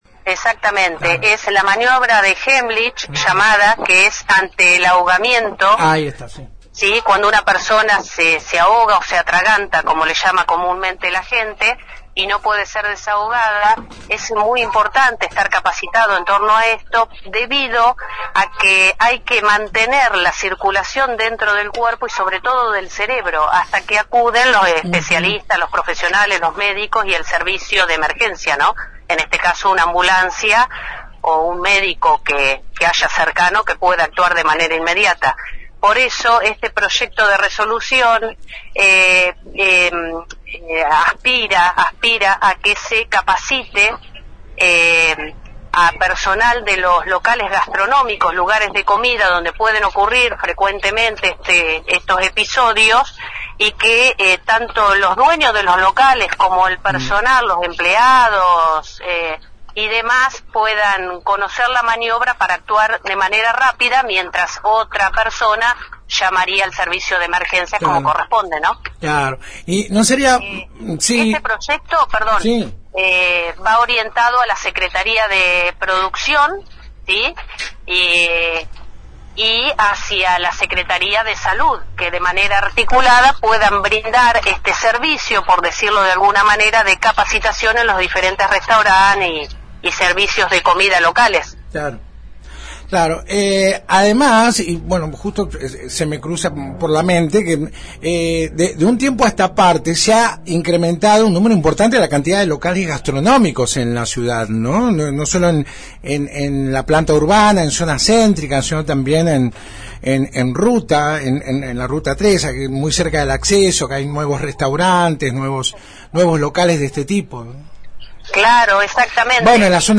La concejal por el Bloque Todos por Las Flores se refirió este jueves en la 91.5 al Proyecto de Resolución que presentará esta noche en una nueva sesión ordinaria del HCD. Se trata ni más ni menos que la implementación de cursos RCP y Técnicas de Heimlich en locales gastronómicos.